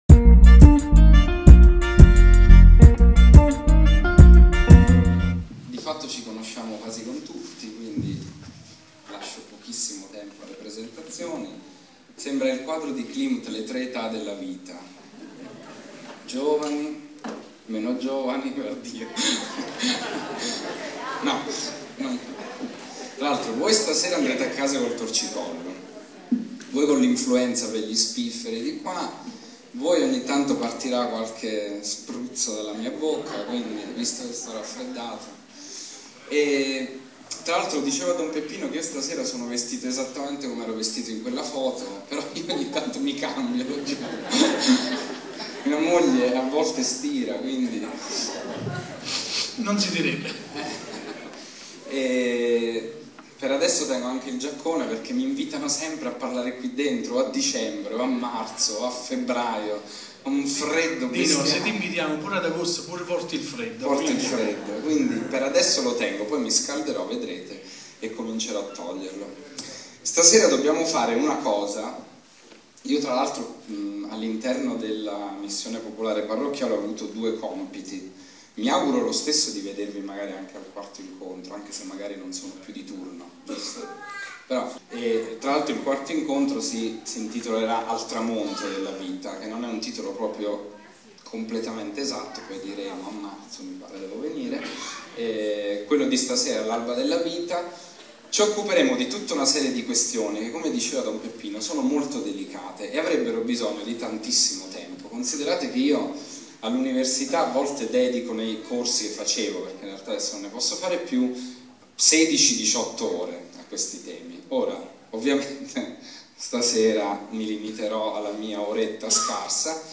Conferenza